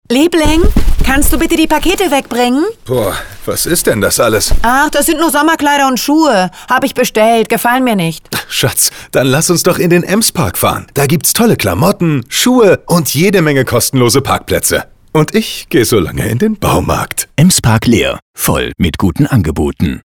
Funkspot "Voll mit guten Angeboten"